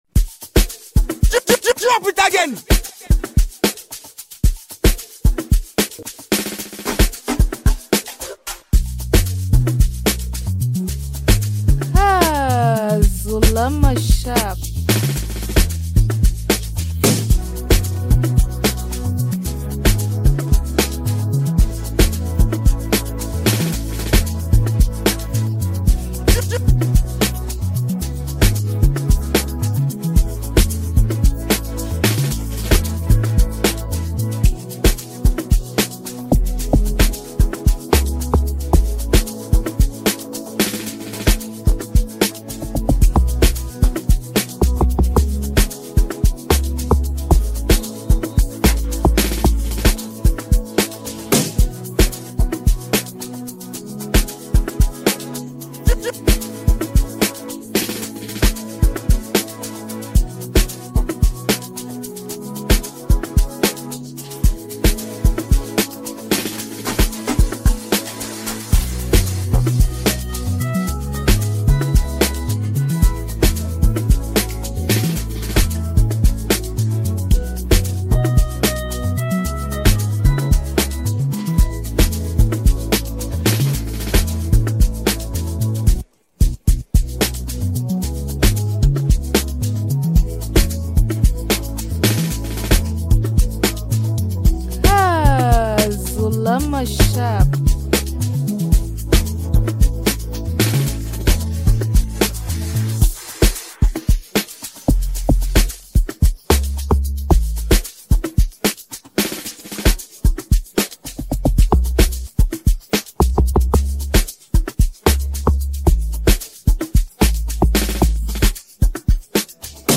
amapiano beats